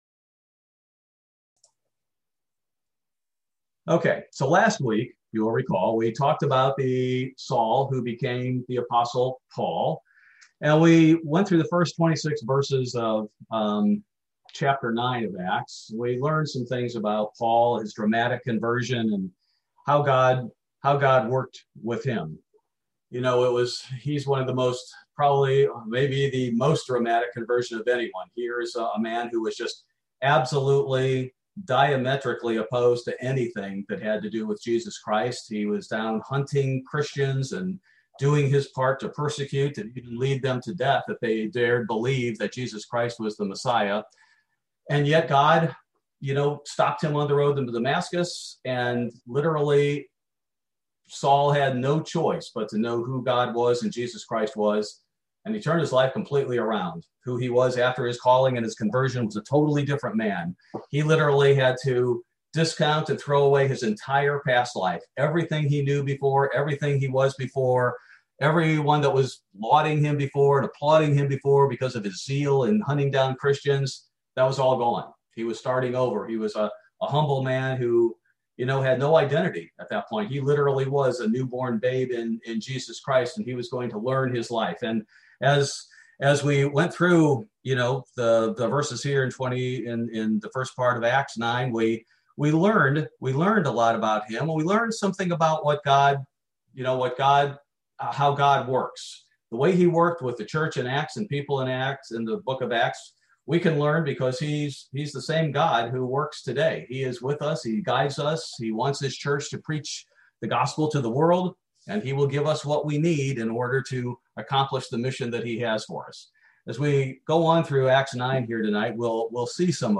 Bible Study: July 21, 2021